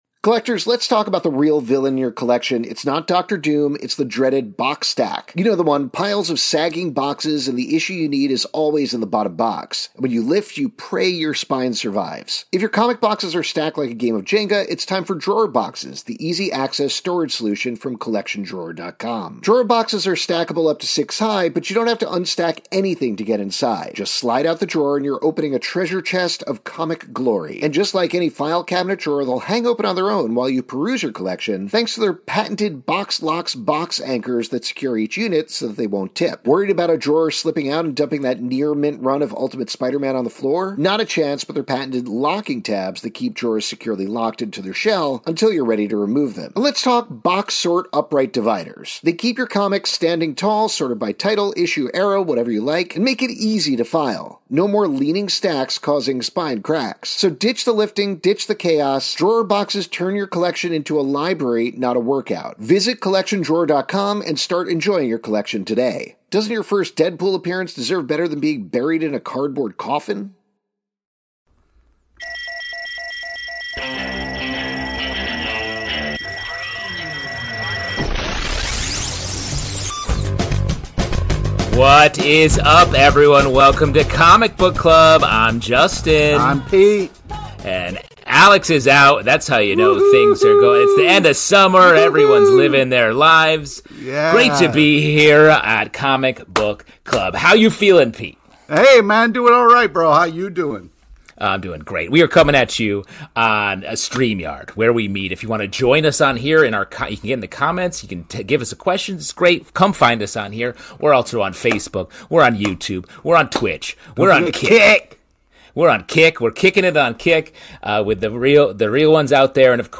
On this week's live show